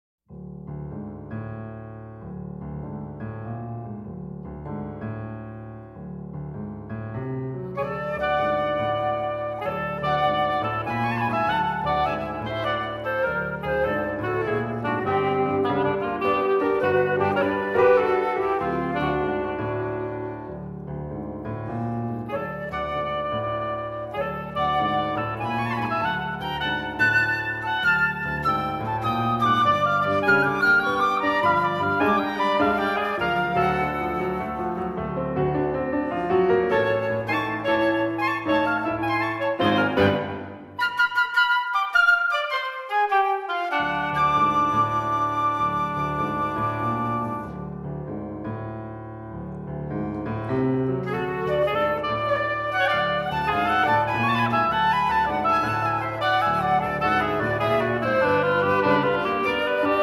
for flute, oboe and piano